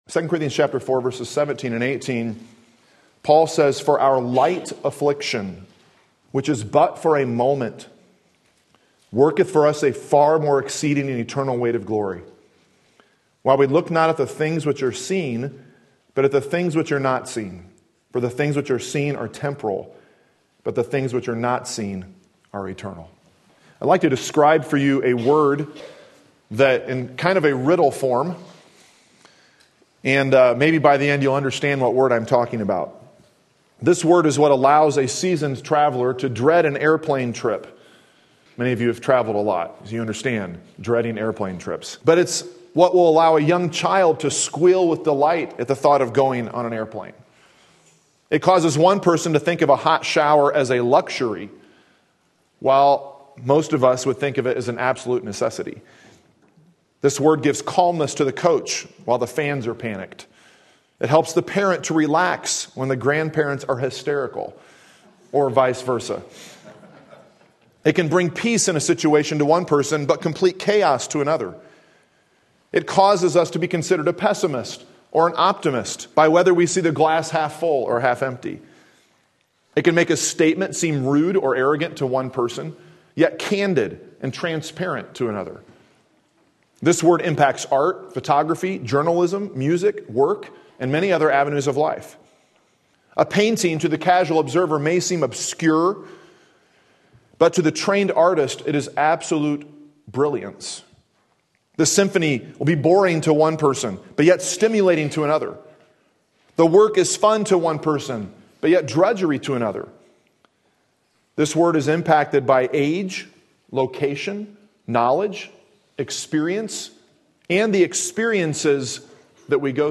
Sermon Link
Perspective in Suffering 2 Corinthians 4-5 Sunday Morning Service